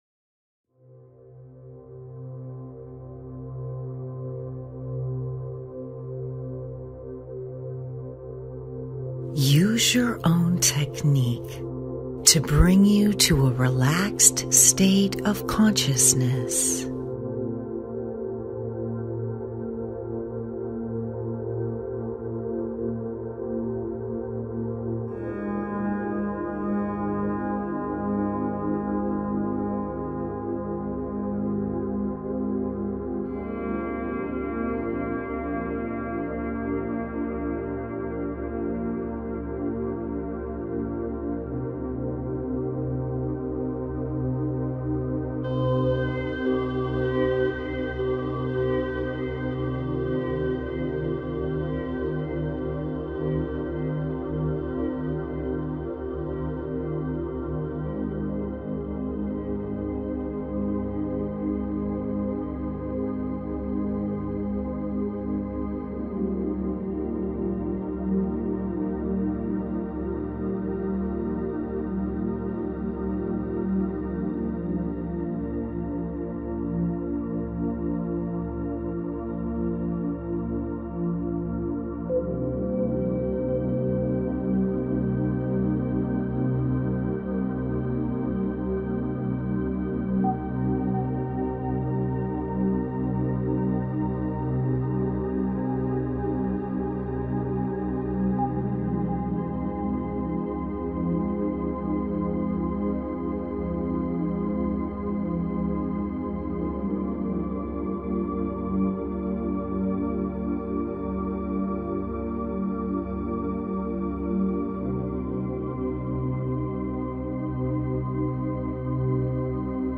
Divine Intervention Activation - English guided audio
divine_intervention_activation_-_english_guided_audio.mp3